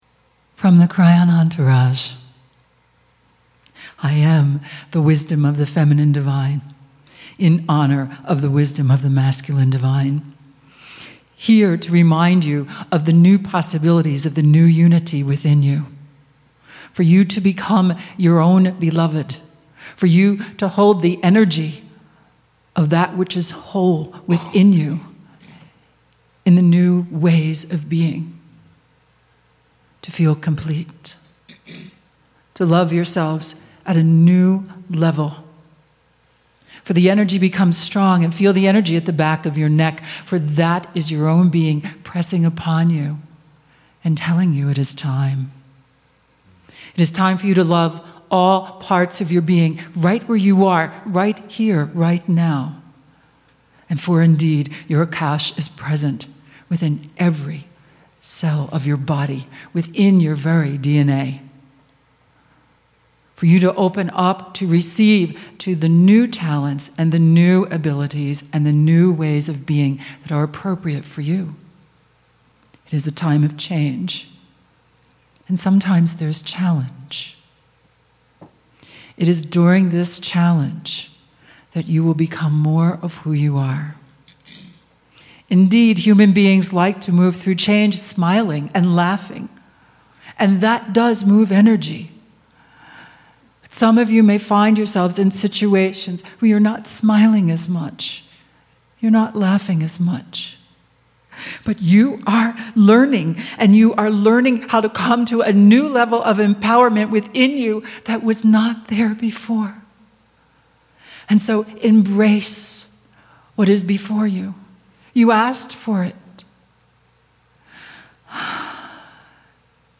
2013 "The Time Capsule of Gaia" Live Channelling